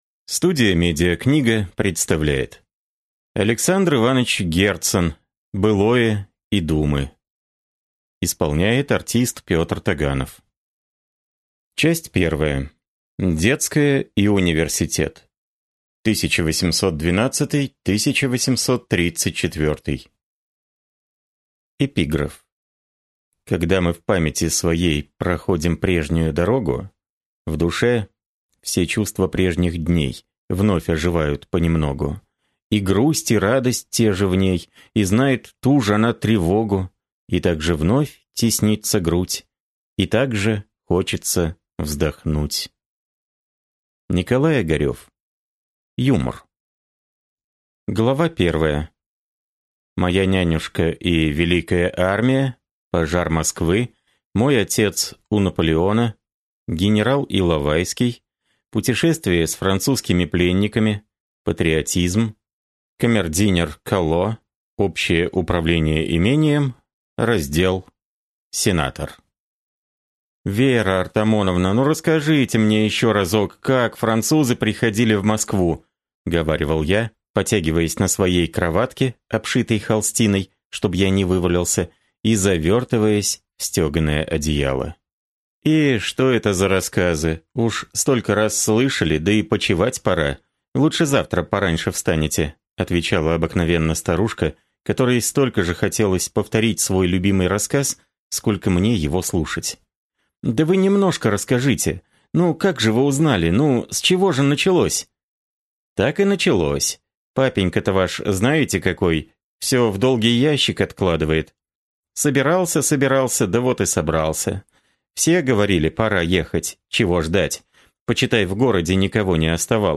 Аудиокнига Былое и думы | Библиотека аудиокниг